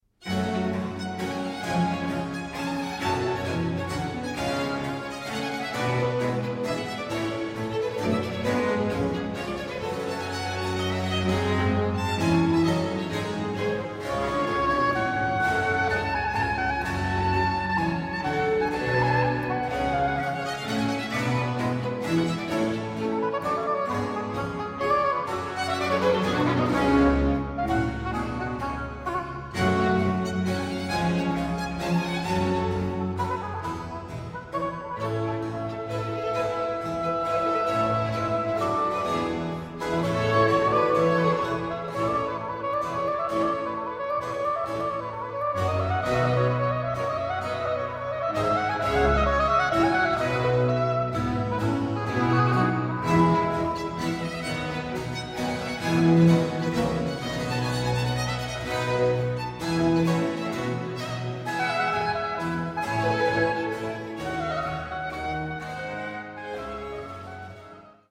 g-Moll für Oboe, Streicher und Basso continuo
4. Satz Allegro (rechte Maustaste)